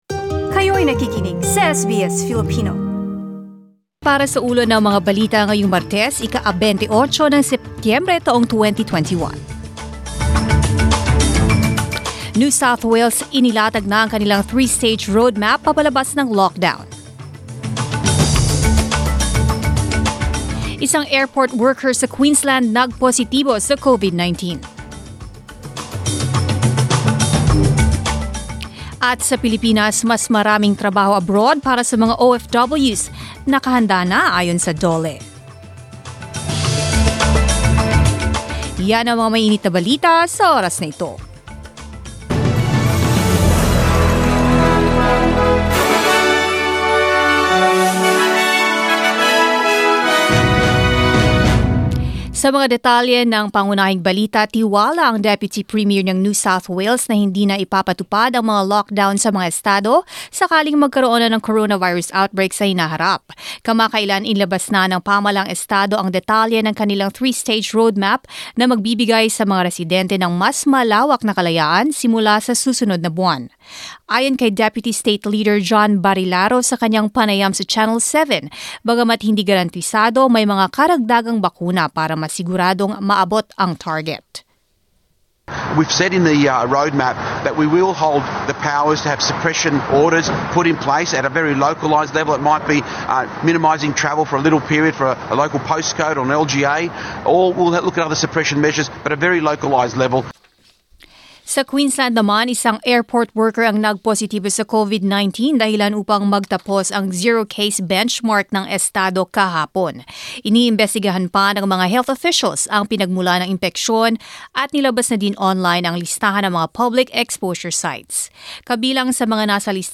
SBS News in Filipino, Tuesday 28 September